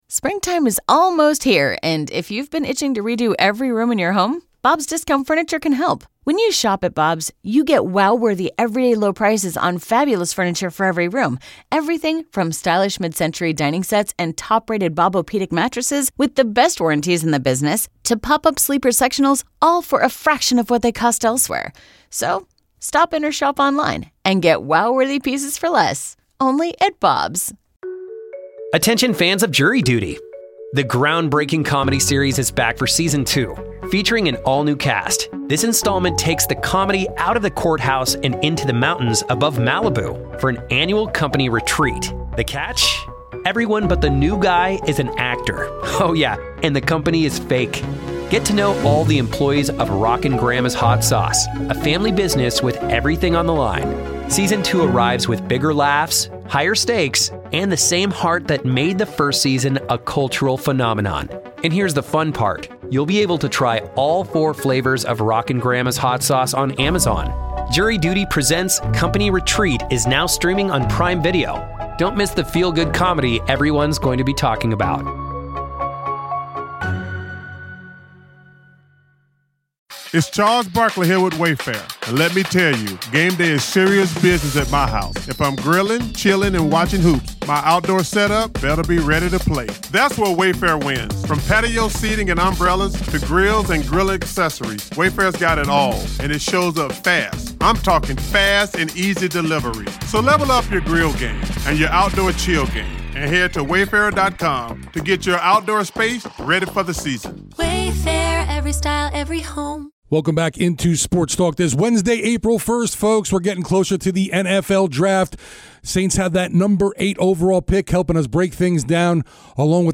Saints interviews, press conferences and more